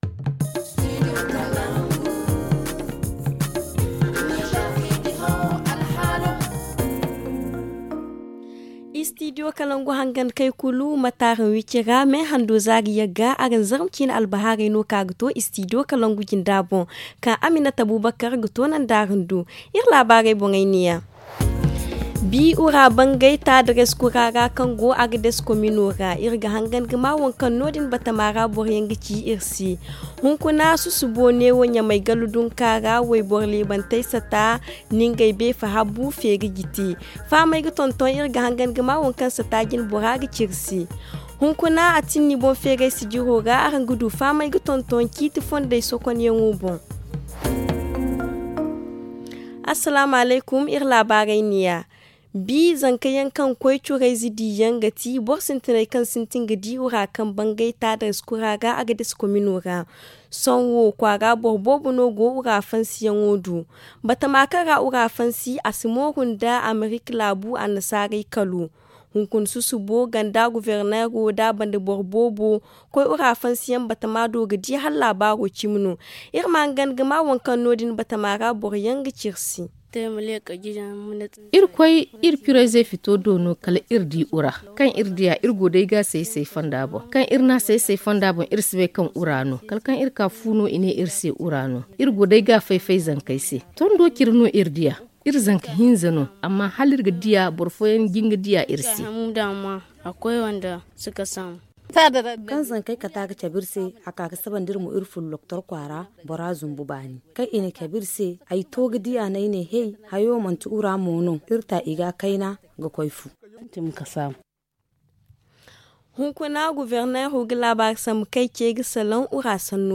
Le journal du 9 mai 2022 - Studio Kalangou - Au rythme du Niger